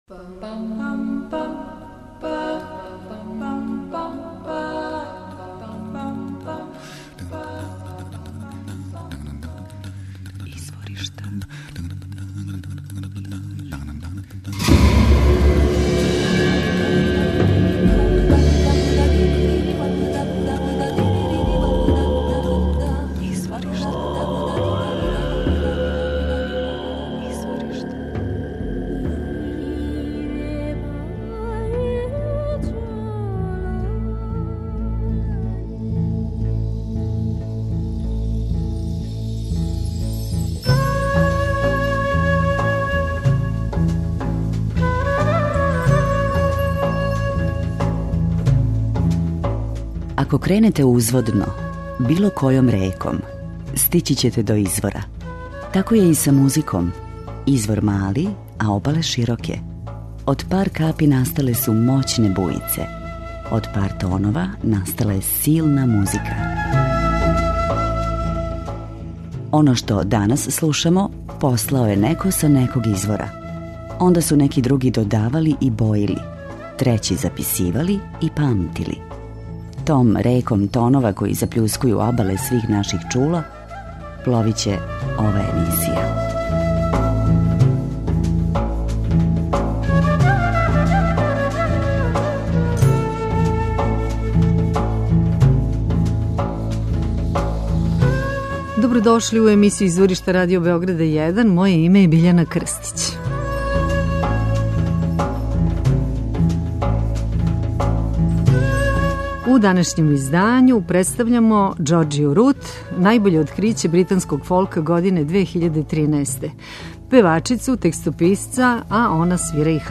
Она свира харфу, пева и пише текстове.